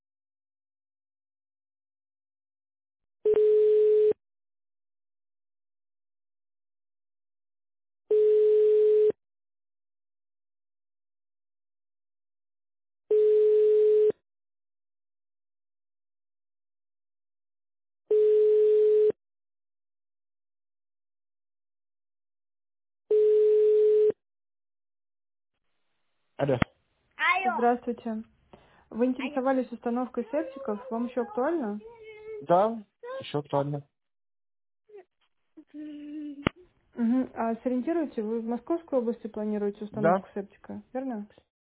Текст озвучивается живым человеческим голосом, что вызывает доверие.
Примеры аудиозаписей "Звонка робота"